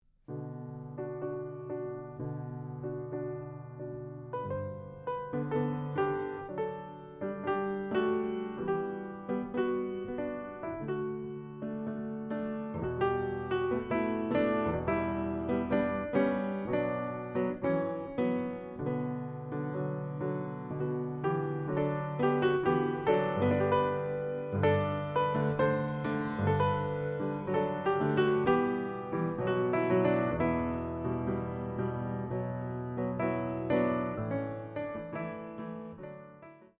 music for piano
Piano
Steinway Grand Piano - model S. 155